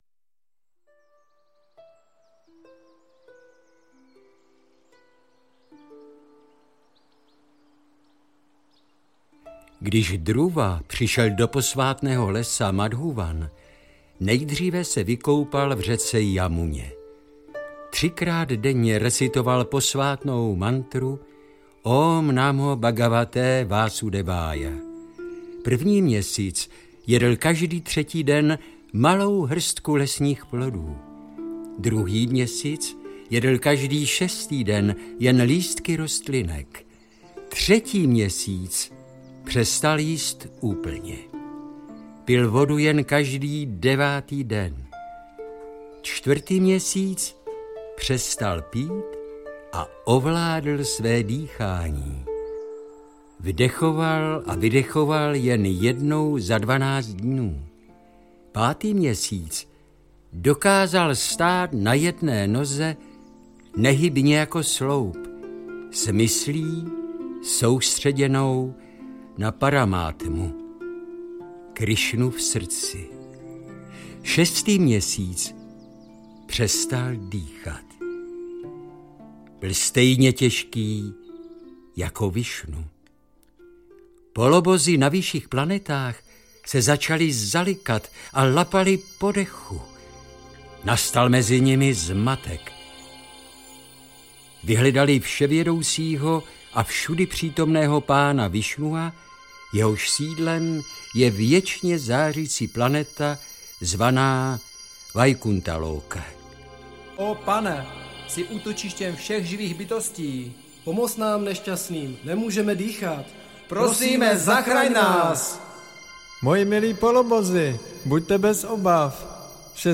Krásný Bhágavatam díl 4. audiokniha
Ukázka z knihy